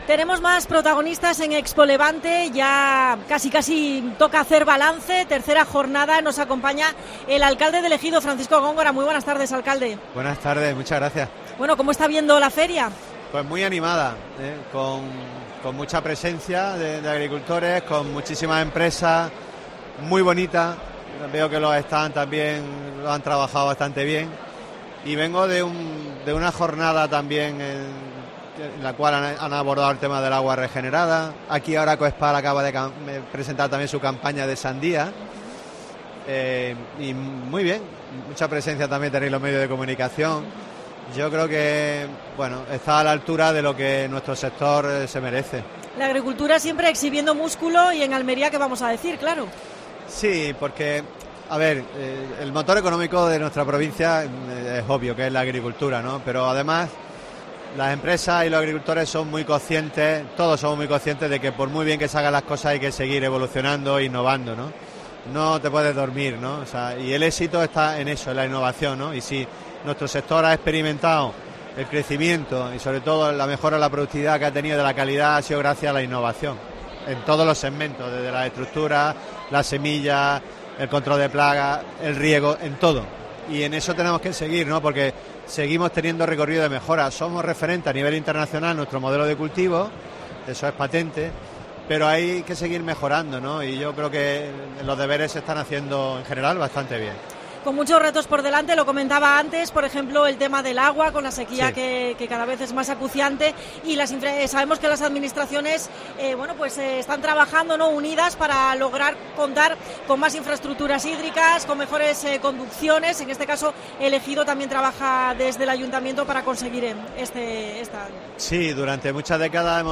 Especial COPE Almería desde ExpoLevante en Níjar. Entrevista a Francisco Góngora (alcalde de El Ejido).